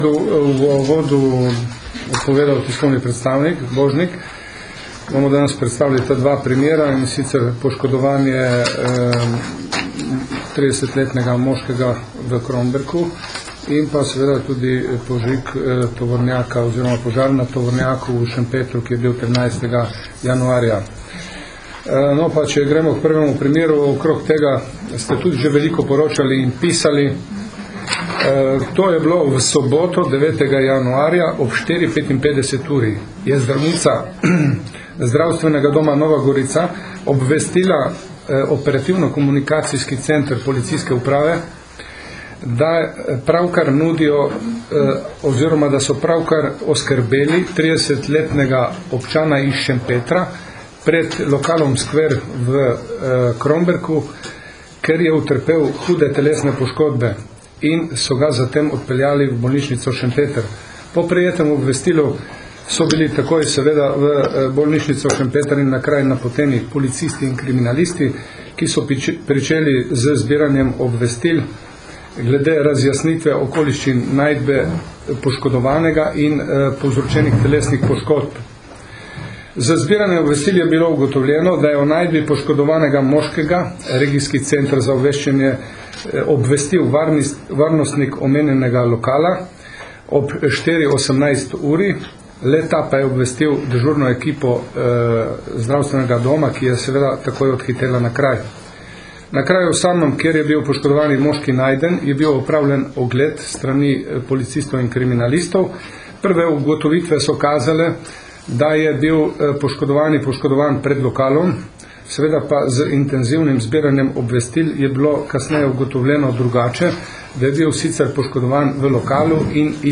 Policija - Odkrili požigalce tovornega vozila v Šempetru pri Gorici - informacija z novinarske konference PU Nova Gorica
Na novinarski konferenci 29. januarja 2010